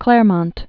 (klârmŏnt)